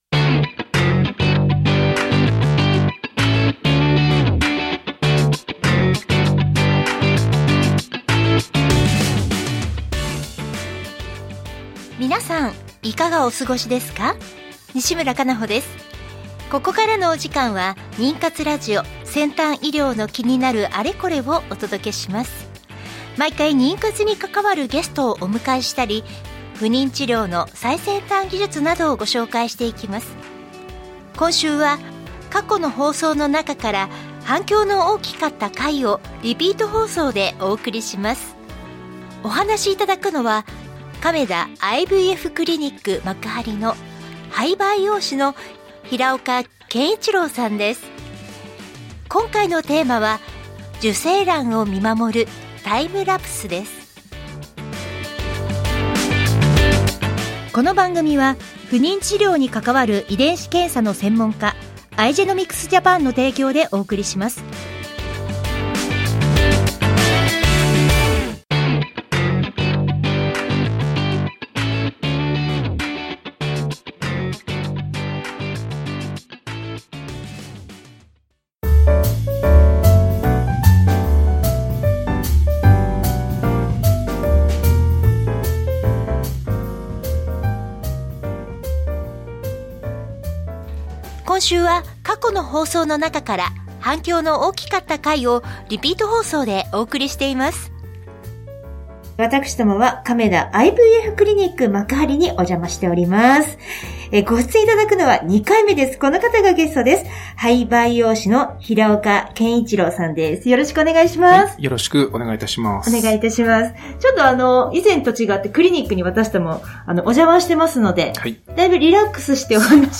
テーマは 受精卵を見守るタイムラプス 反響の大きかったリピート放送!!